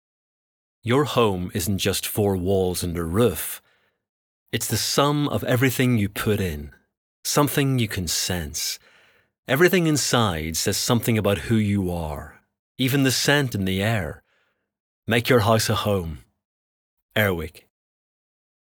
I’m using a Shure PG42 plugged into my Macbook. I’ve built a little booth for myself, it’s not sound proofed but I’ve acoustically dampened it as much as possible. I’m trying to explore my microphone technique, in particular my distance from the microphone, and I’m also trying to up my water intake as I’ve noticed, with some frustration, that I’m inclined to have a clicky mouth.